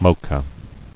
[mo·cha]